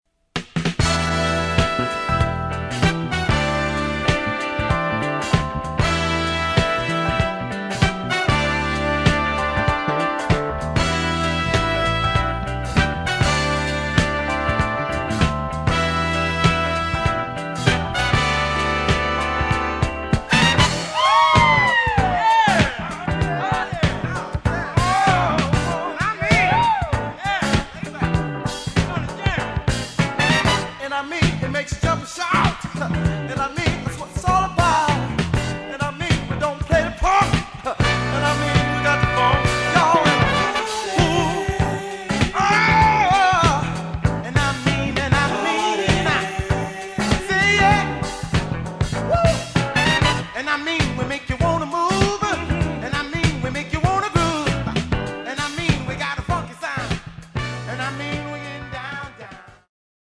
funk groups